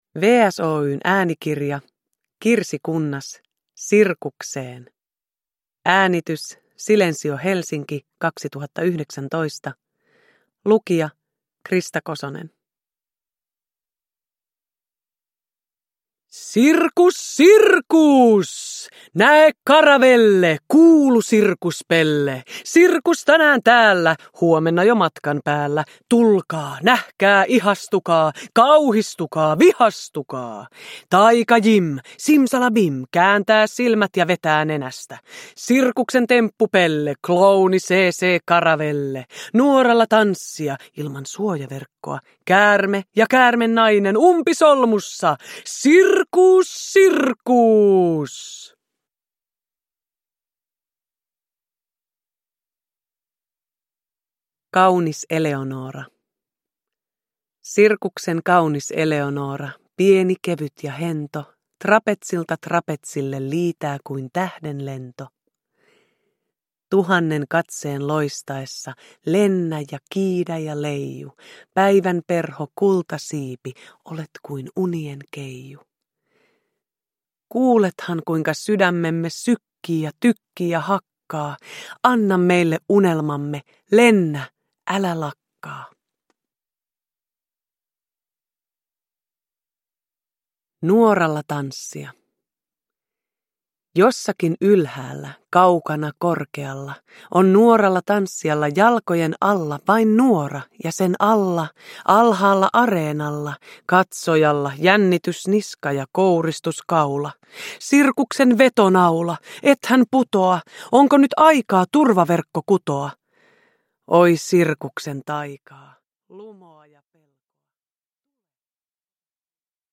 Kirsi Kunnaksen rakastettuja runoja ilmestyy ensi kertaa äänikirjoina Krista Kososen lukemina.
Uppläsare: Krista Kosonen